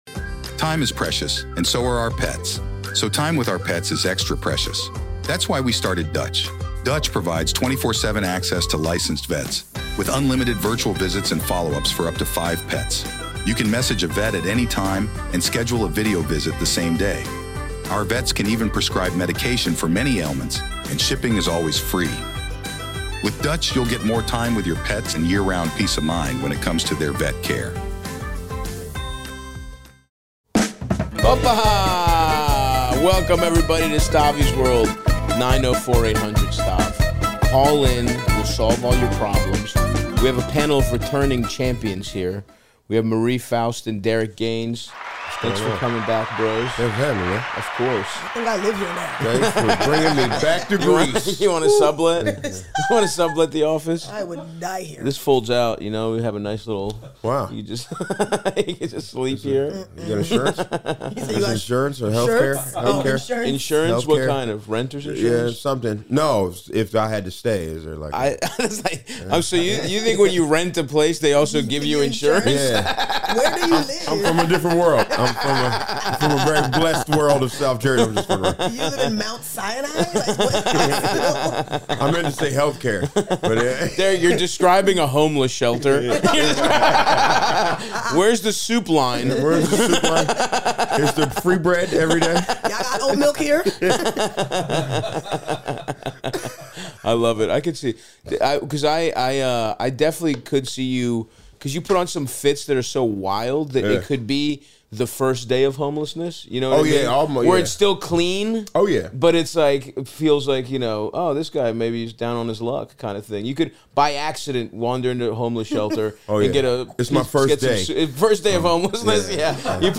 and Stav help callers including a woman who feels extremely guilty about wanting to break up with her boyfriend, and a man wondering if he should live with his hot friend who he knows isn't interested in him.